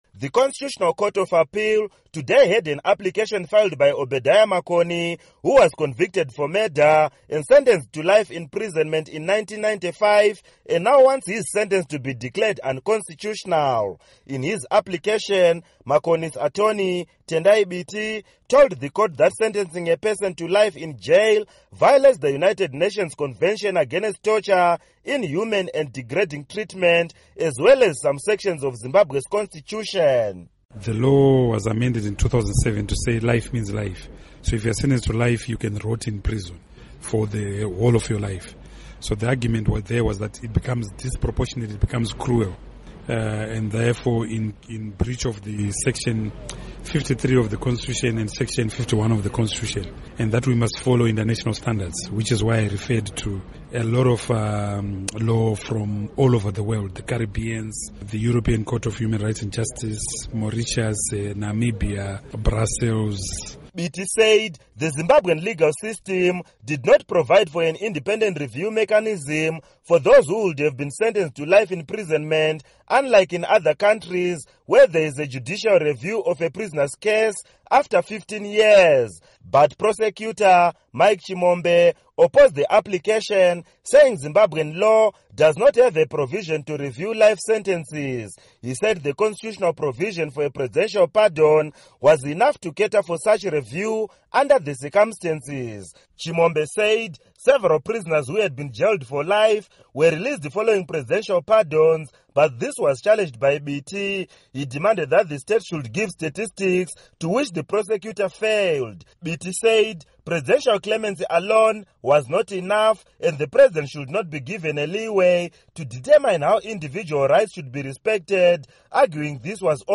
Report